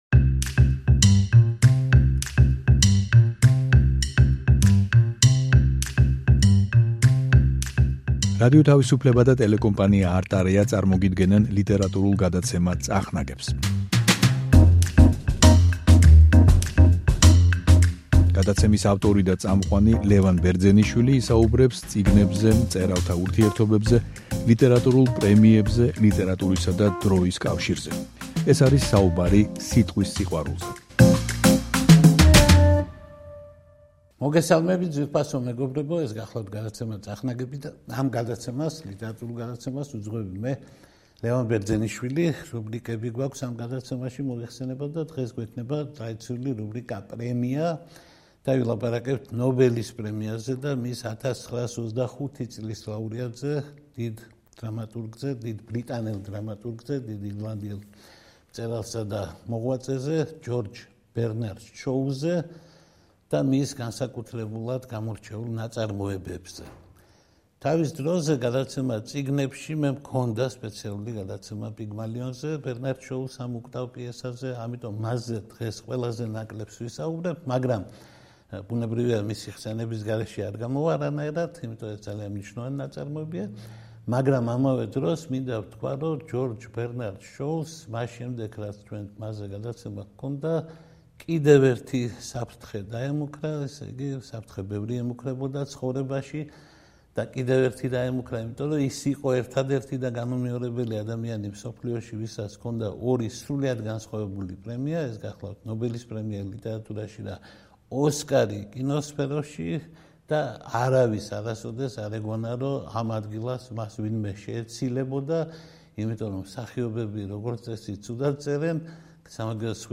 ლიტერატურული გადაცემა „წახნაგები“ რუბრიკით „პრემია“გთავაზობთ საუბარს ყველაზე პრესტიჟულ ლიტერატურულ პრემიაზე, ნობელის პრემიაზე და მის 1925 წლის ლაურეატზე, სახელგანთქმულ ბრიტანელ, კერძოდ, ირლანდიელ დრამატურგზე, „მეორე შექსპირად“ წოდებულ ჯორჯ ბერნარდ შოუზე.